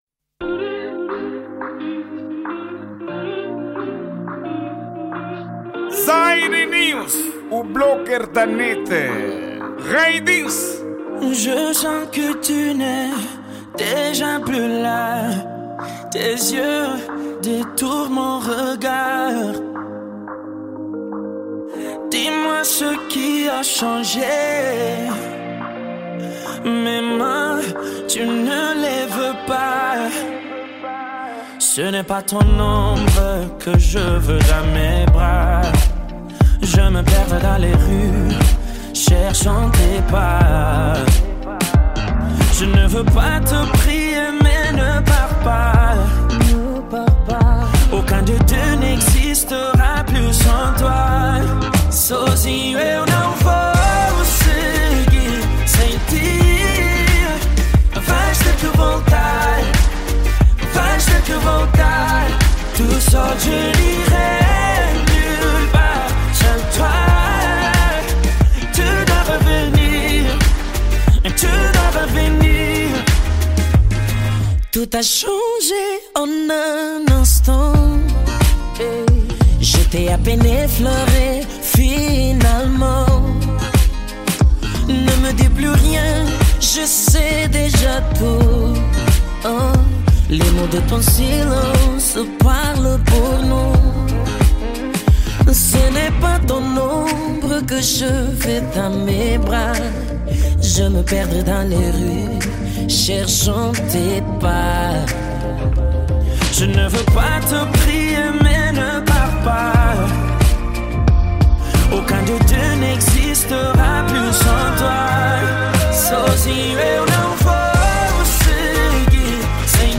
Gênero:Afro Pop